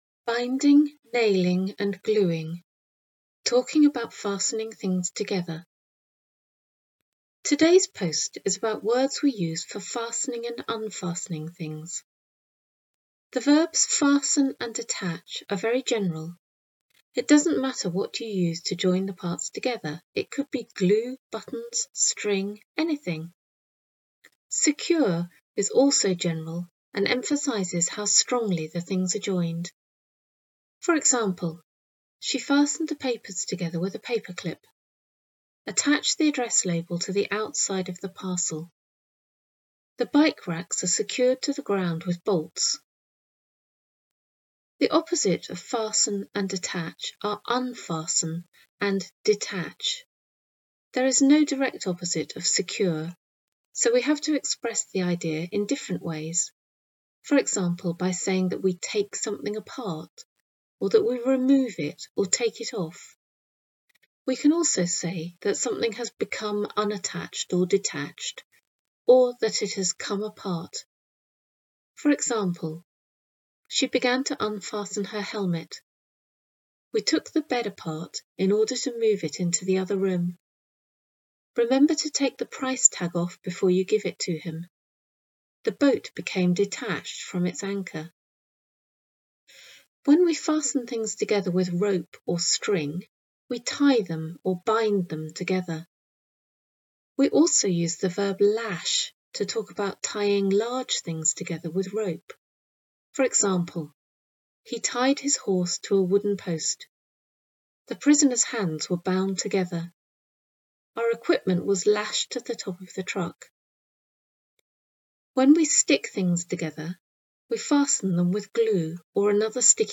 Listen to the author reading this blog post: